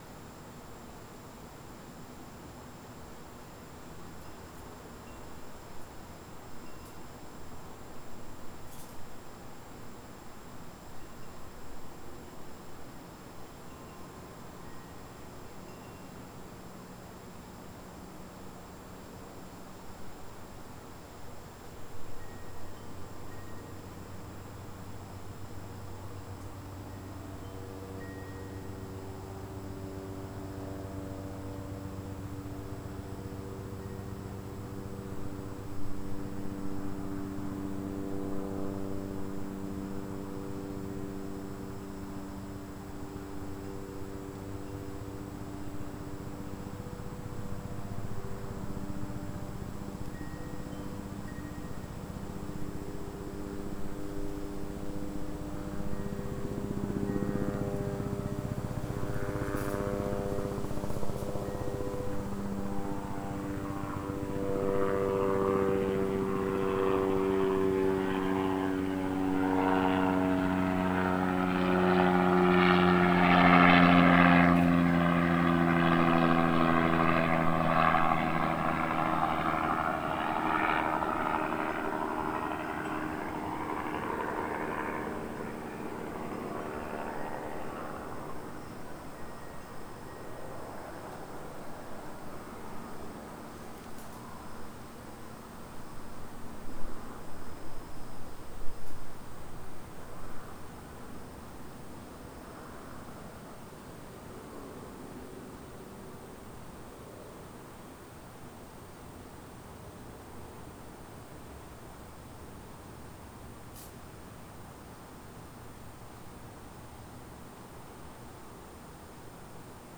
Sample #7: Helicopter (02:11) (11.5MB/file).
B-format files for experimental mic.
Helicopter passing over my front yard on April 29, 2008.